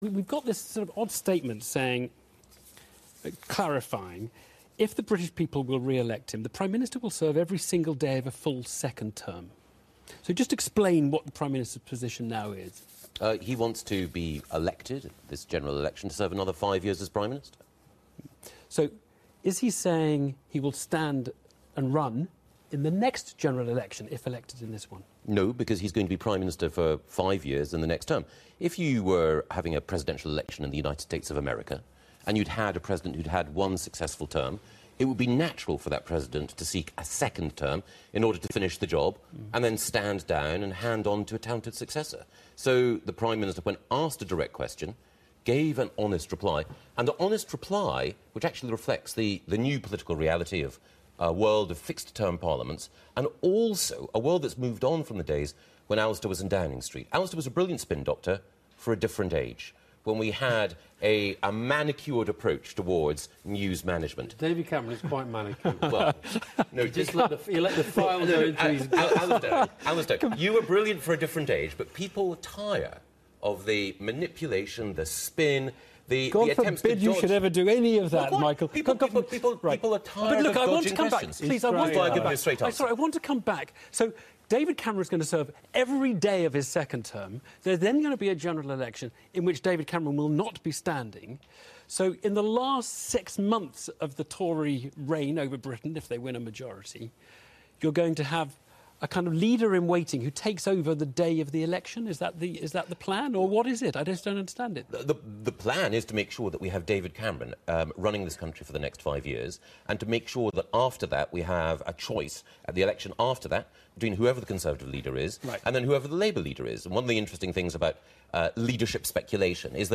Michael Gove appears on Newsnight, alongside Alastair Campbell, to explain an BBC interview in which David Cameron said he would not serve a third term as Prime Minister. The Chief Whip insisted that Mr Cameron would serve a full five-year second term if successful in May, but said he was not 'surprised' by the comments. 23 March 2015.